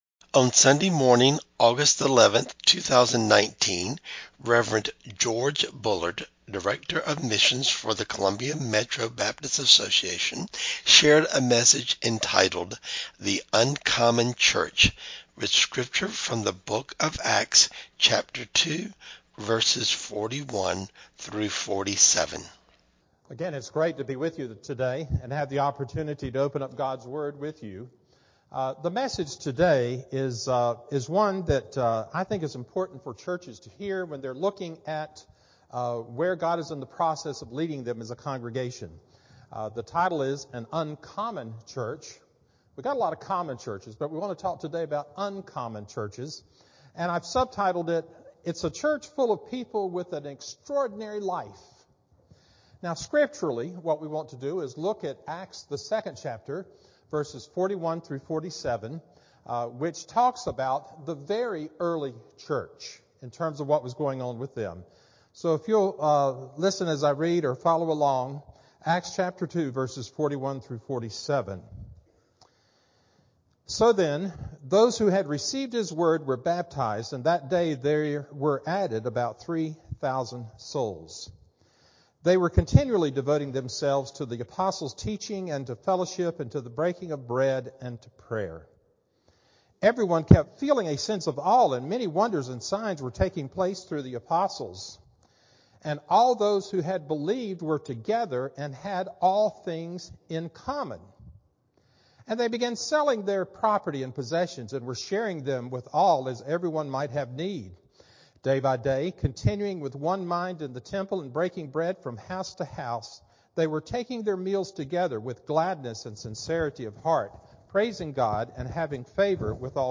8-11-19-AM-Sermon-CD.mp3